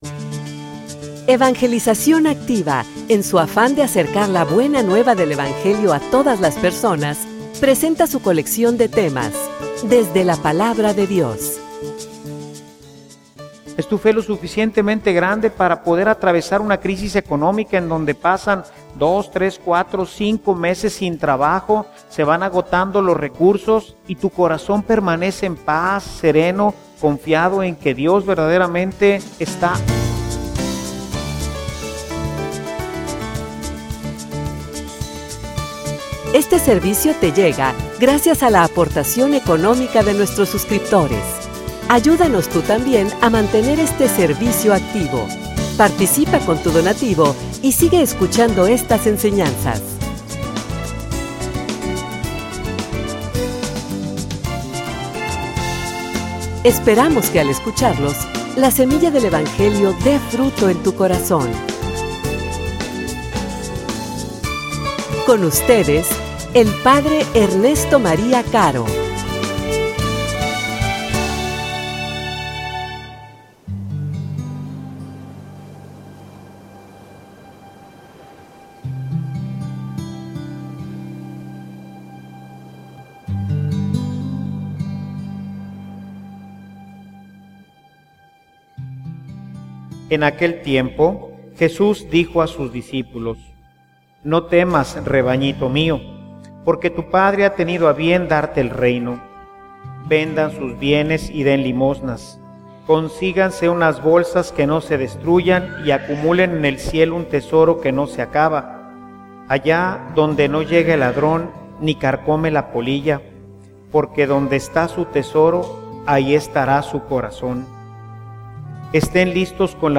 homilia_Dale_espacio_a_Dios_en _tu_vida.mp3